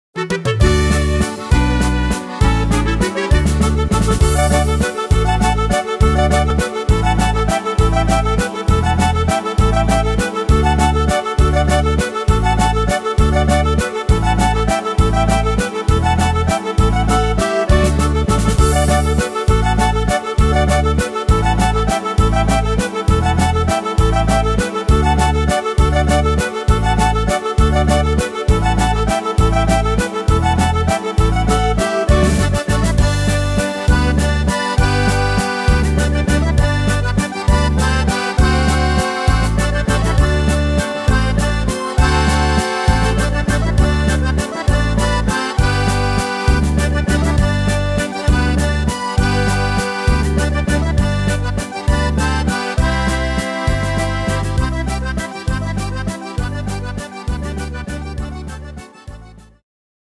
Tempo: 200 / Tonart: D-Dur